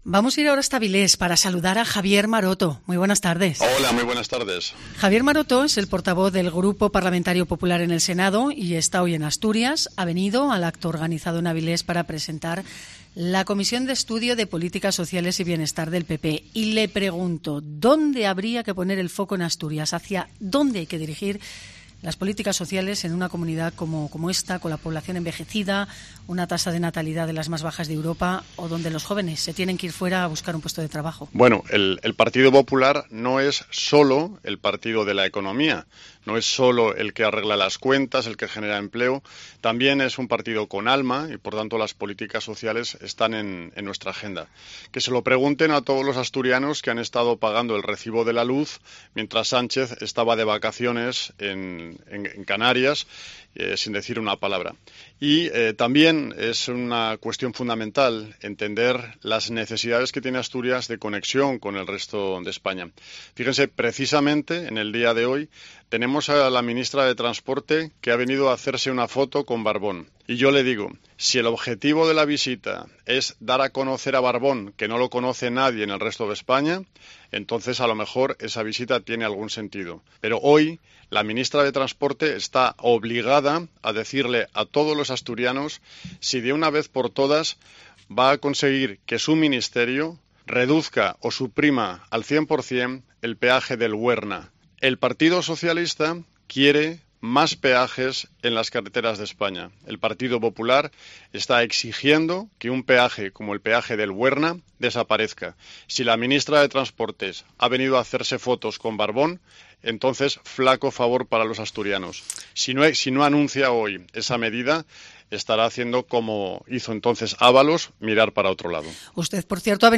Entrevista con el portavoz del grupo parlamentario popular en el Senado
Entrevista a Javier Maroto (PP)